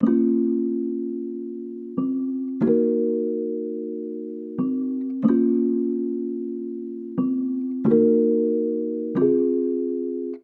TAINY_92_melodic_loop_keys_lam_Ebmin.wav